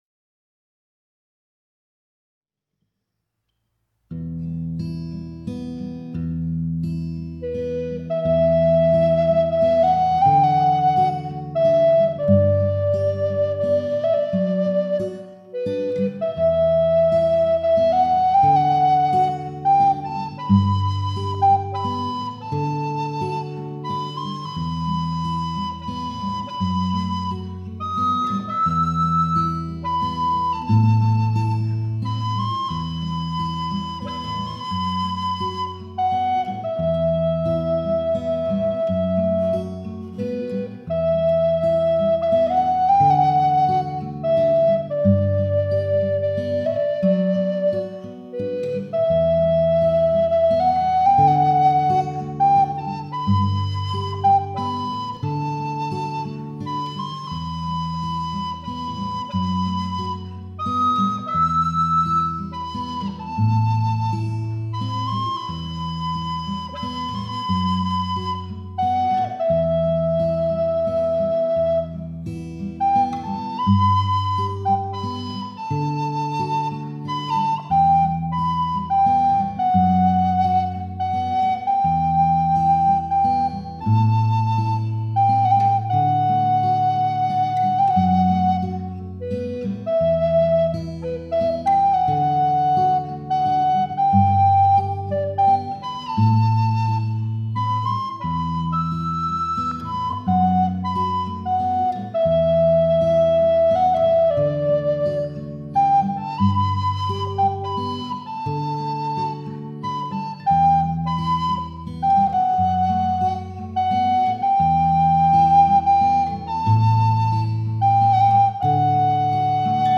Second Reflection   A moody waltz
Second Reflection-ar.mp3